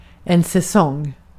Ääntäminen
IPA : /ˈsizən/